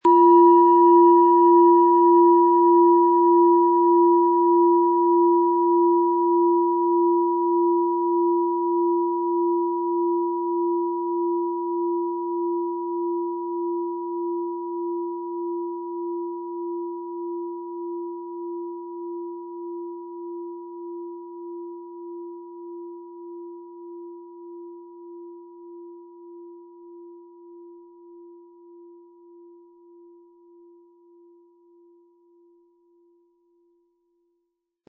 Planetenton 1
Planetenschale® Begeistert sein können & Leicht fühlen mit Chiron, Ø 14,9 cm inkl. Klöppel
Sie möchten den schönen Klang dieser Schale hören? Spielen Sie bitte den Originalklang im Sound-Player - Jetzt reinhören ab.
MaterialBronze